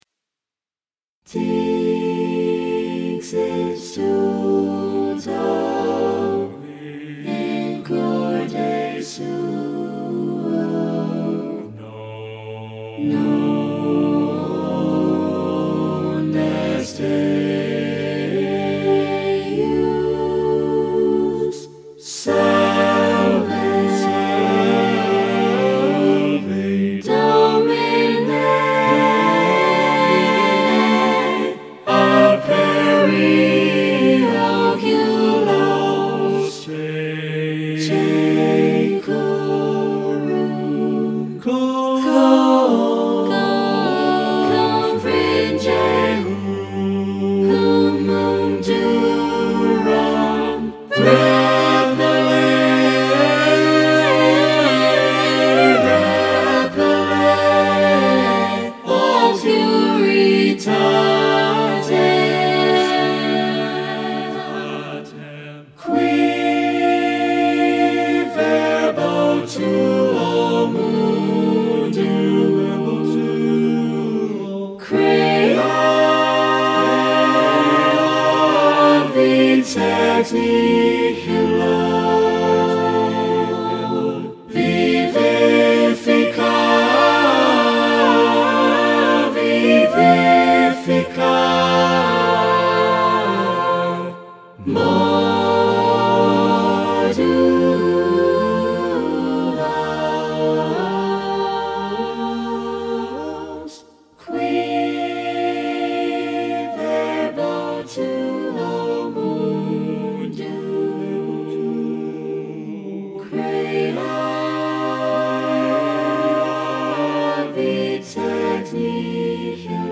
Choral SSAATTBB, a capella.
Original text and music.
Vocal parts rendered with ACE Studio.